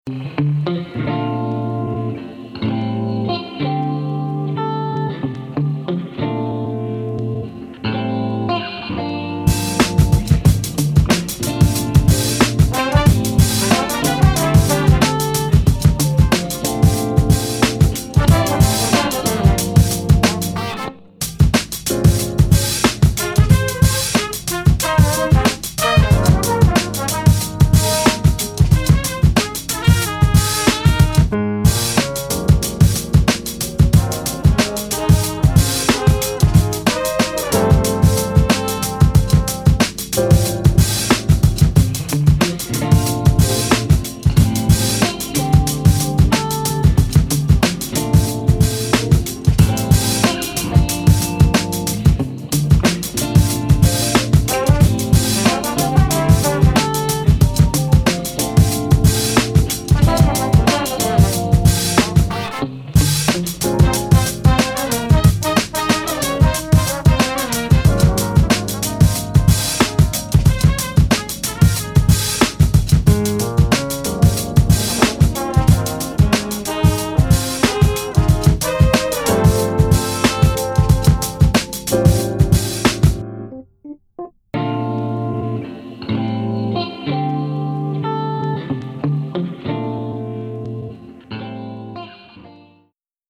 Soul, R&B
F Major